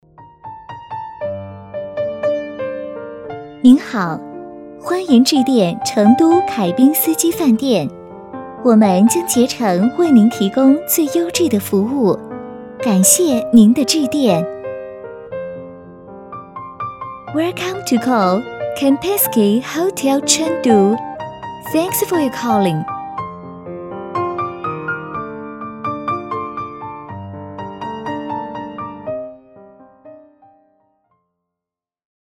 女国66_其他_彩铃_中英文成都凯宾斯基_甜美.mp3